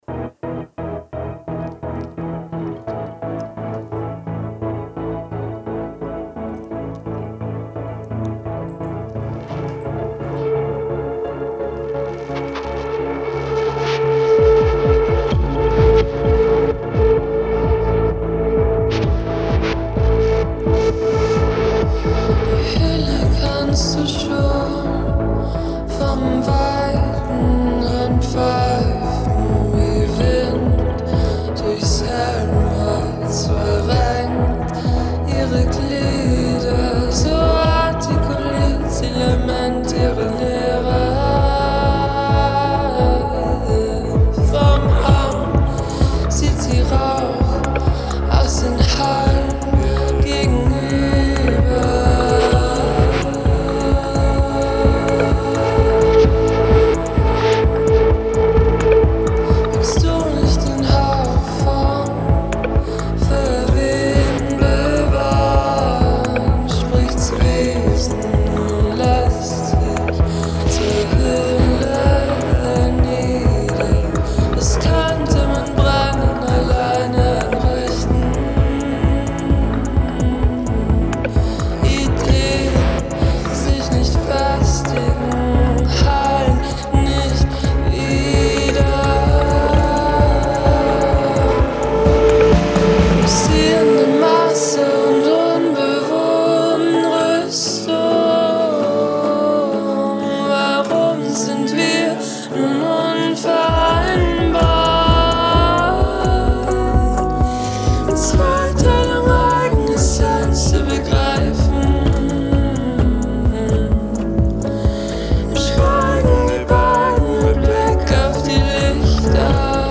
Experimental-Noise-Pop-Konzeptalbum